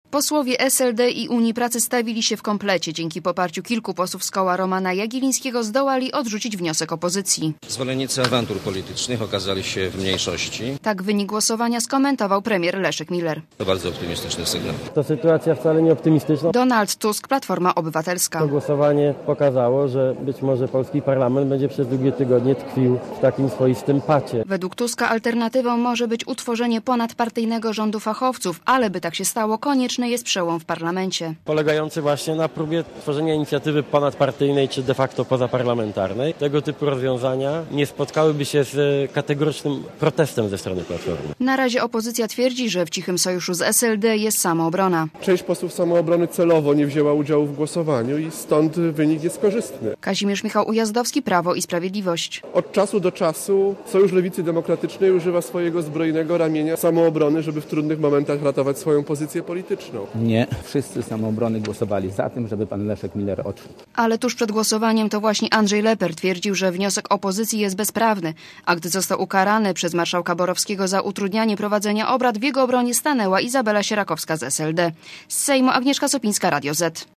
Relacja reportera Radia Zet (607Kb)